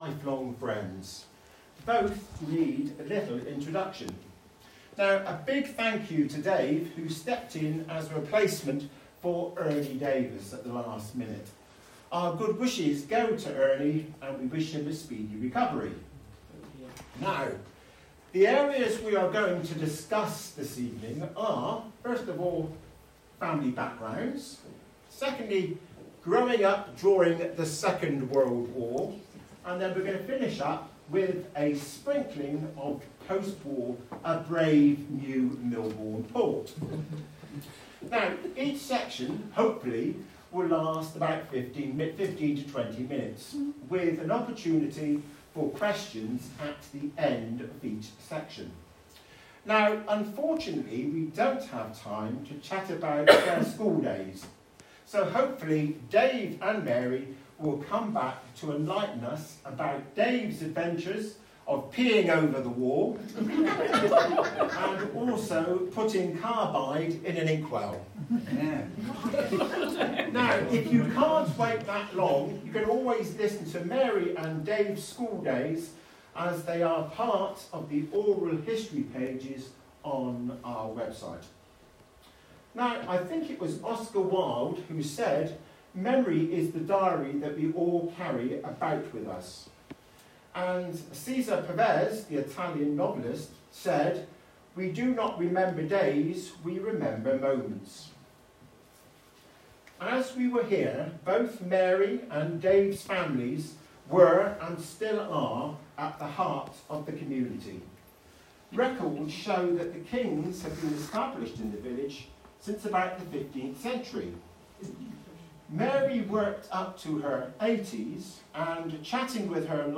Oral History Project – Milborne Port History and Heritage Group